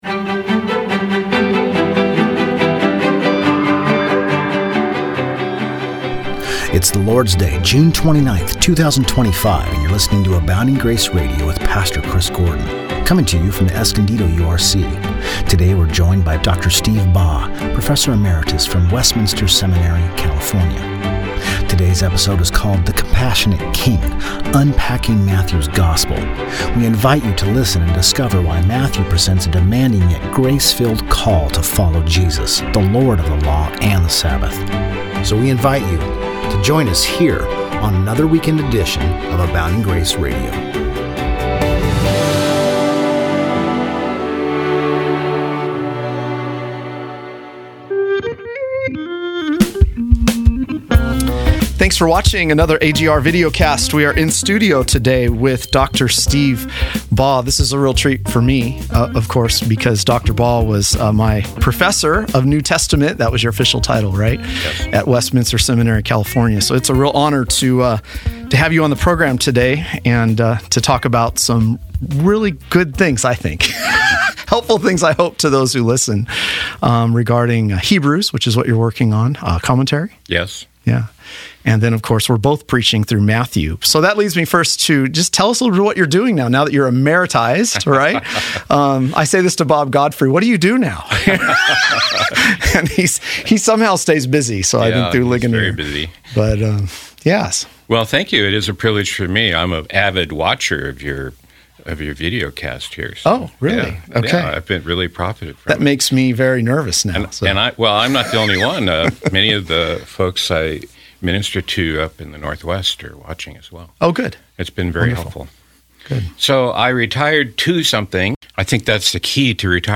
Radio Broadcasts